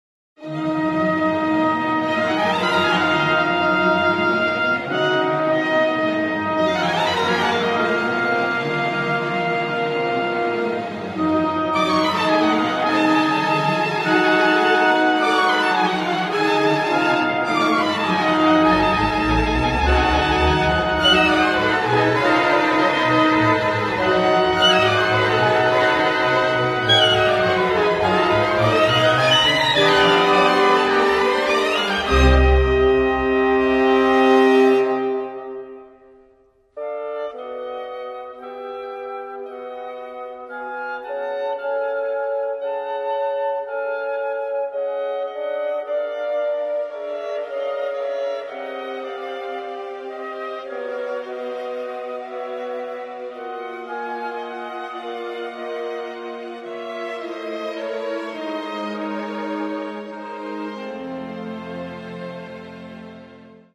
Каталог -> Класична -> Камерна
для сопрано та камерного оркестру